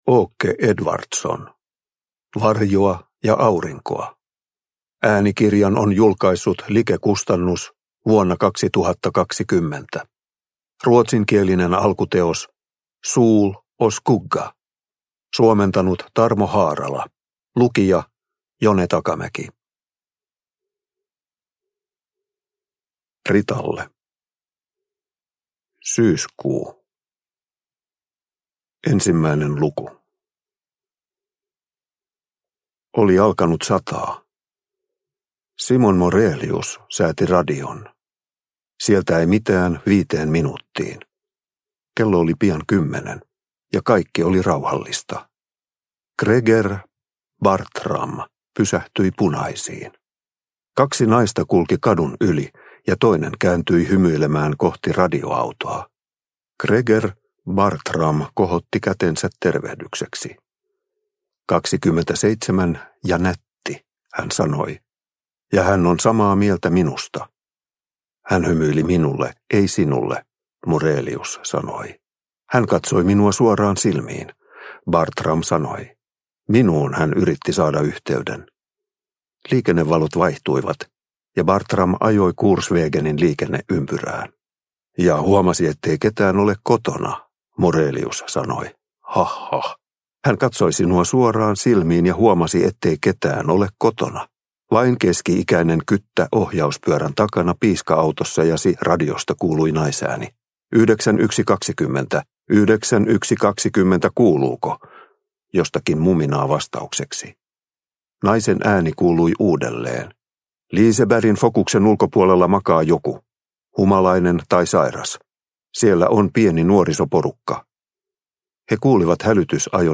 Varjoa ja aurinkoa – Ljudbok – Laddas ner